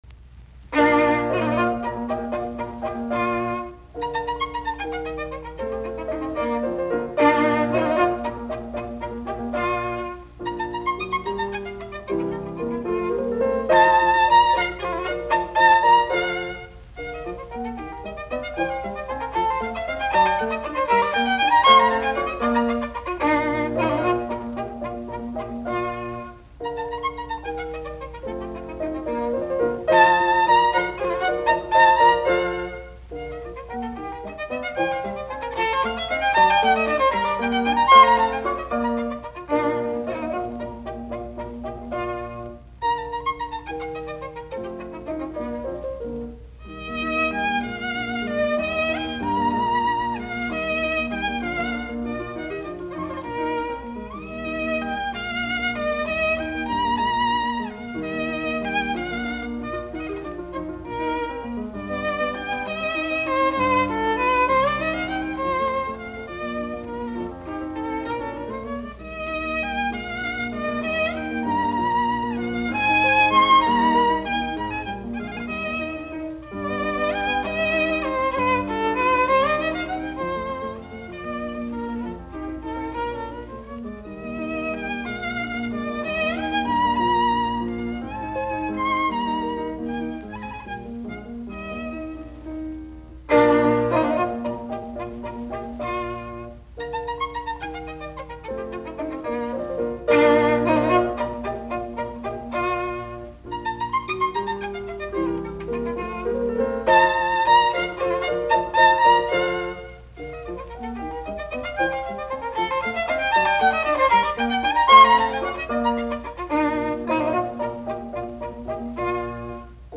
Soitinnus: Viulu, piano.